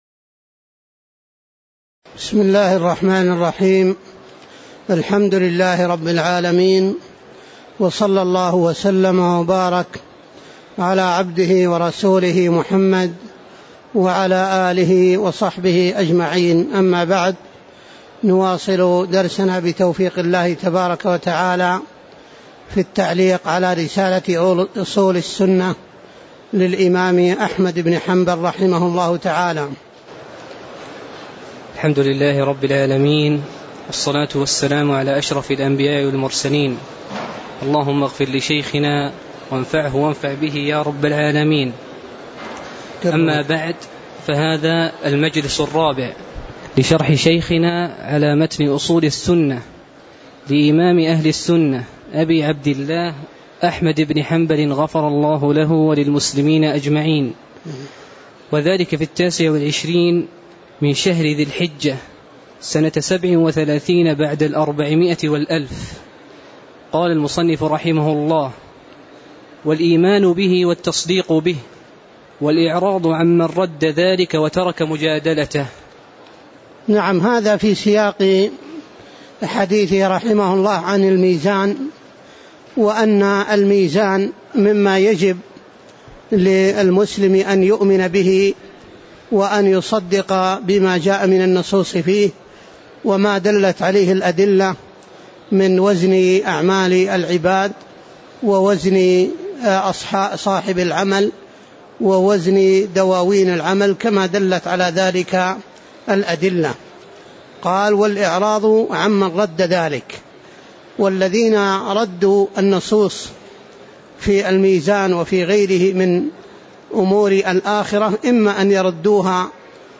تاريخ النشر ٣٠ ذو الحجة ١٤٣٧ المكان: المسجد النبوي الشيخ